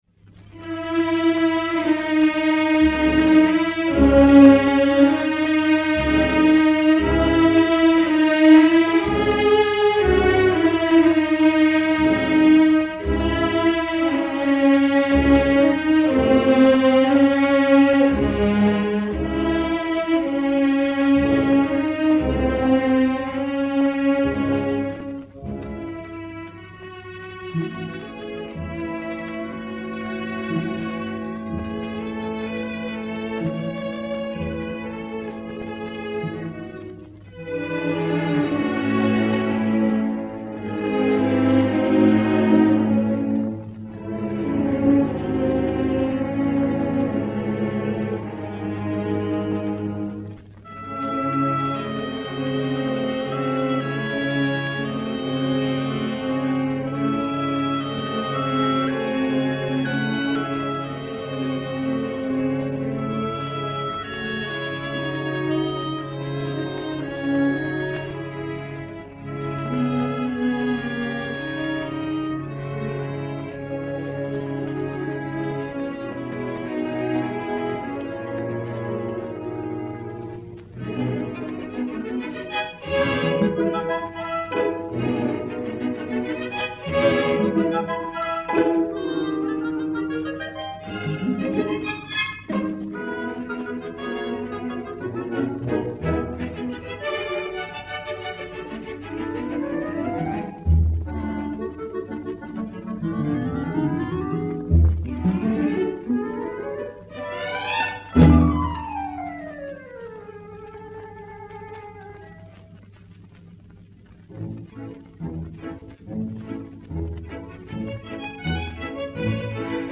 Suite d'orchestre des Deux Pigeons
Orchestre Symphonique